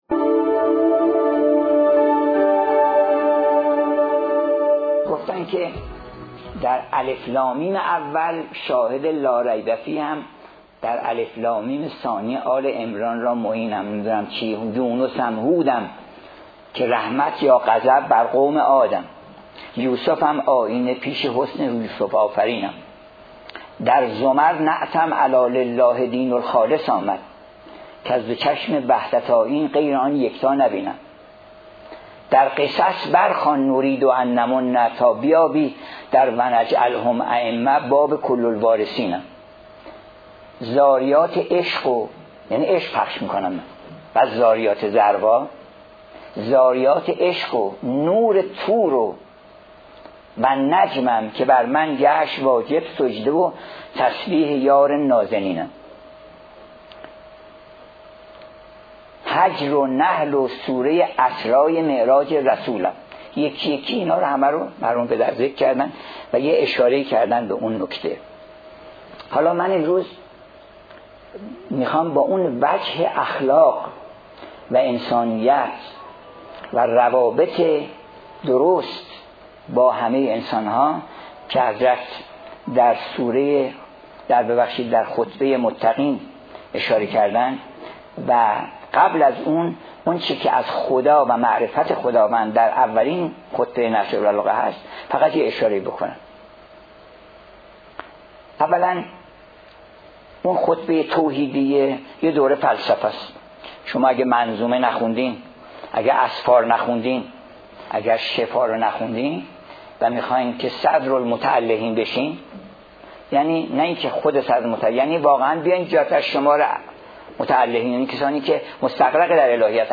سخنراني دكتر قمشه‌اي